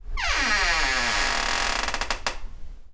Squeaky Door Open
squeaky-door-open-1.ogg